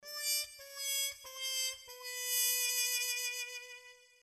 Wimpy Trumpet.mp3 (70.25 KB)